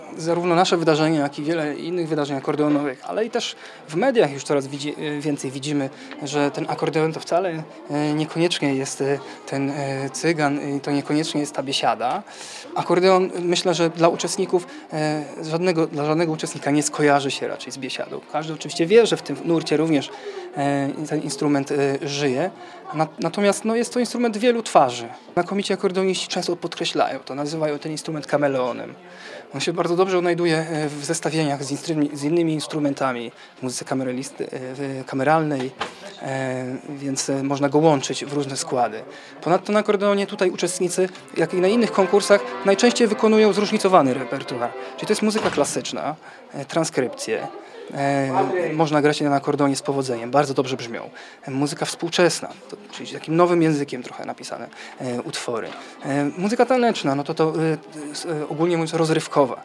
Ponad 150 młodych akordeonistów uczestniczyło w piątek (20.04) w III Międzynarodowym Konkursie Akordeonowym w Suwałkach.